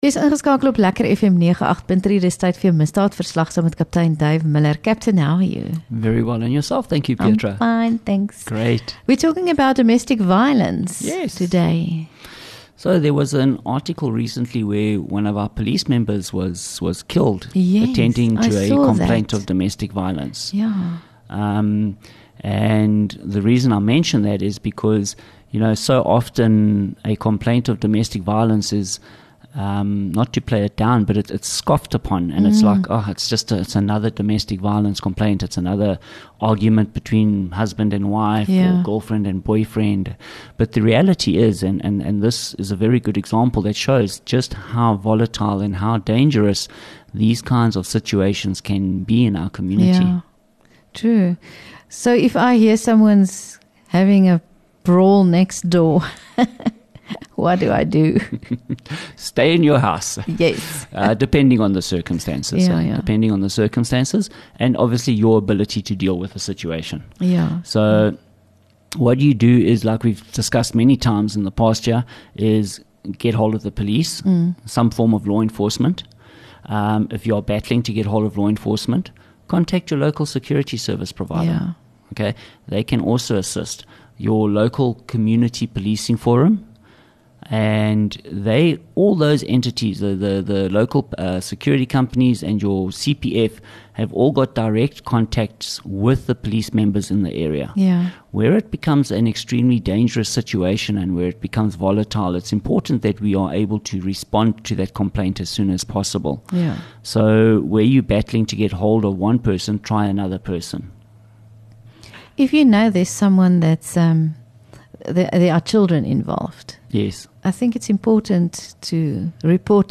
LEKKER FM | Onderhoude 28 May Misdaadverslag